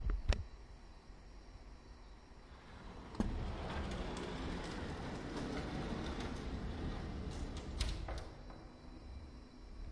滑动门
描述：推拉门打开/关闭